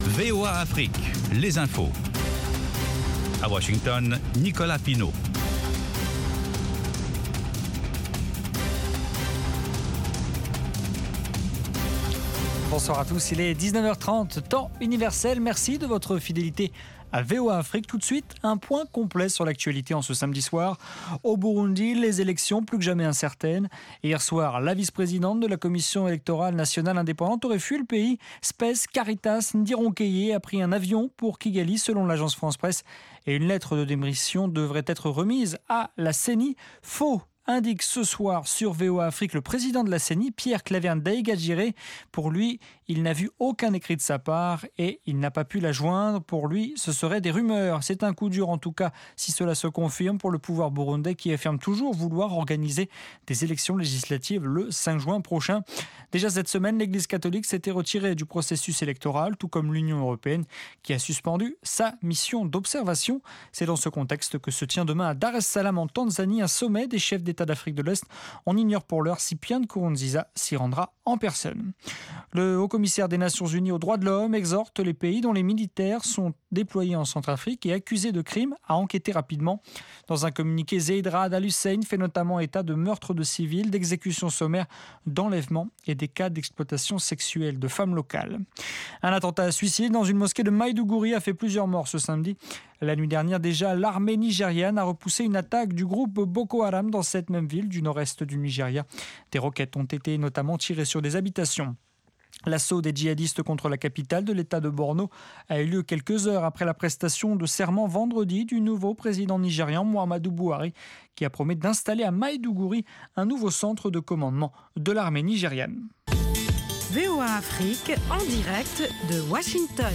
5min Newscast